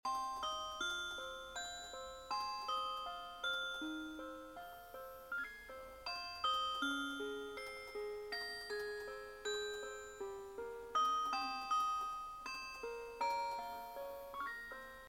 The Sound On This Clock Sound Effects Free Download
the sound on this clock is pleasing to my ears